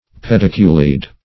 pediculidae - definition of pediculidae - synonyms, pronunciation, spelling from Free Dictionary